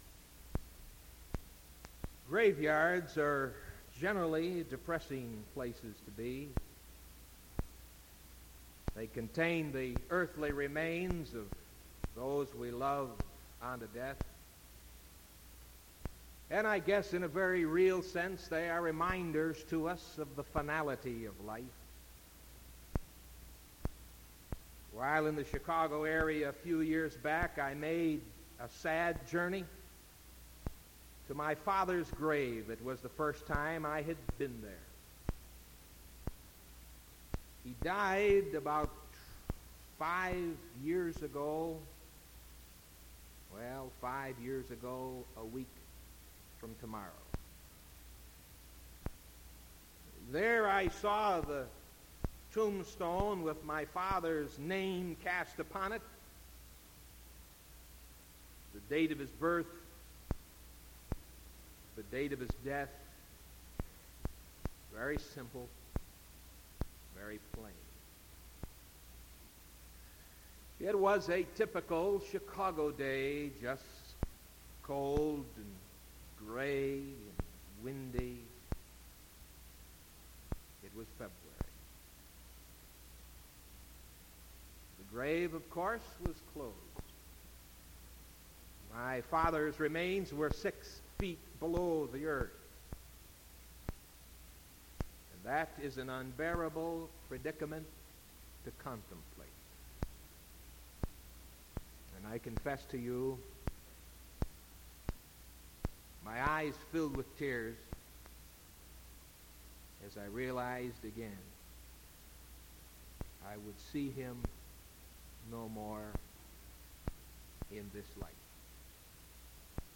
Sermon from April 14th 1974 AM